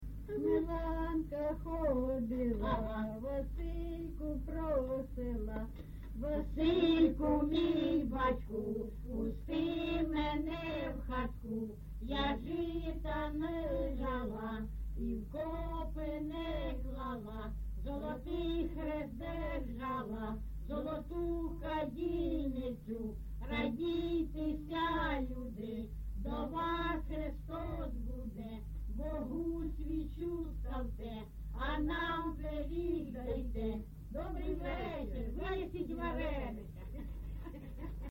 ЖанрЩедрівки